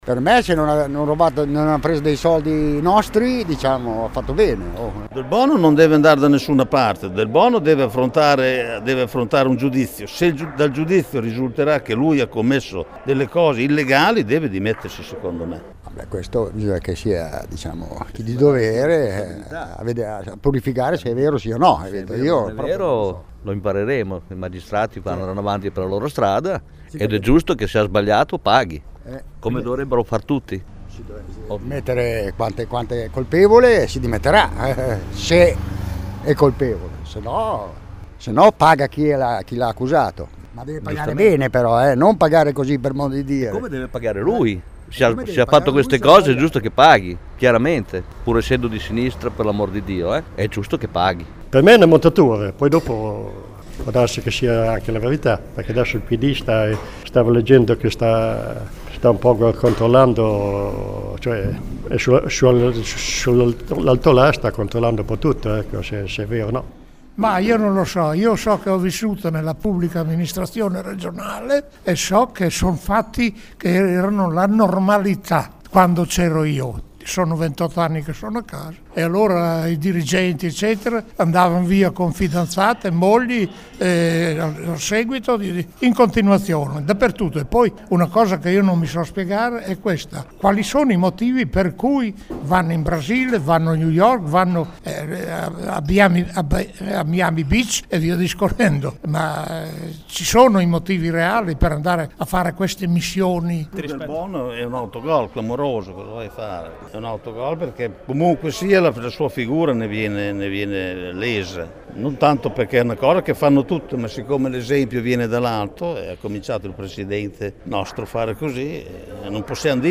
Il nostro inviato ha raccolto alcune voci tra gli elettori di Flavio Delbono.